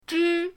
zhi1.mp3